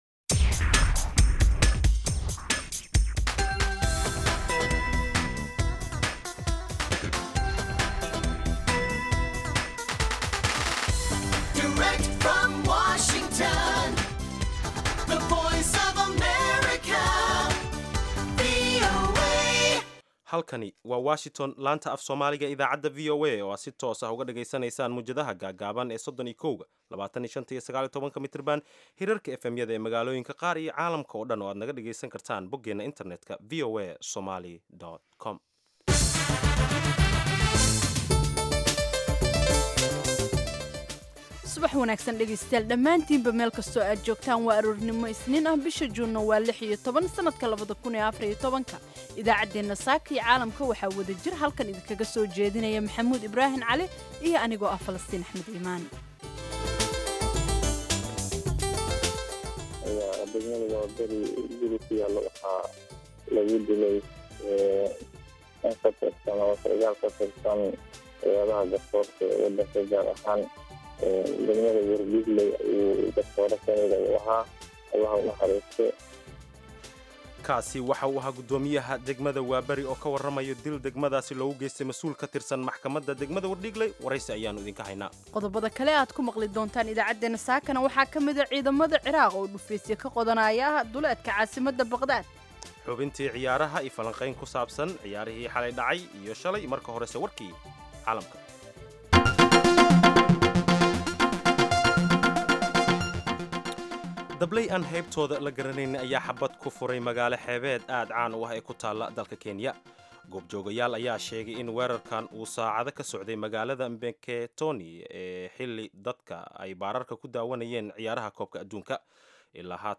Idaacadda Subaxnimo ee Saaka iyo Caalamka waxaad ku maqashaa wararkii habeenimadii xalay ka dhacay Soomaaliya iyo waliba caalamka, barnaamijyo, wareysi xiiso leh, ciyaaraha, dhanbaallada dhagaystayaasha iyo waliba wargeysyada caalamku waxay saaka ku waabariisteen.